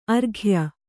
♪ arghya